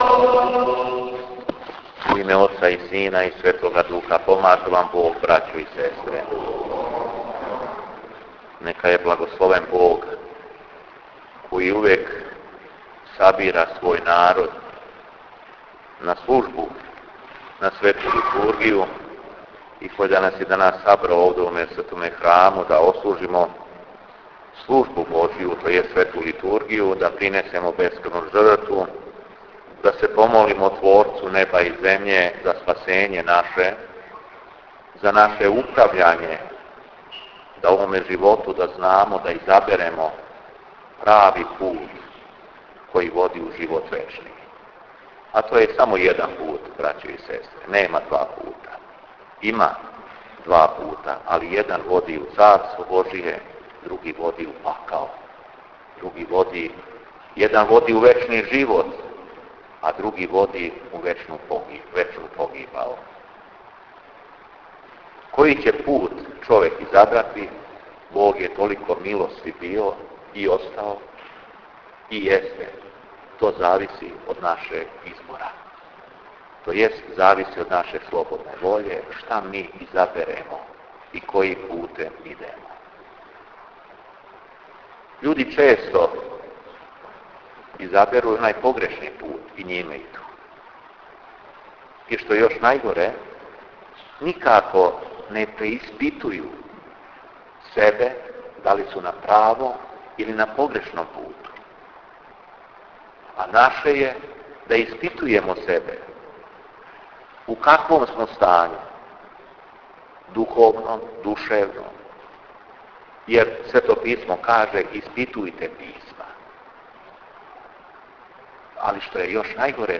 Беседа Епископа шумадијског Г.Јована у храму Св.Кнеза Лазара у Белошевцу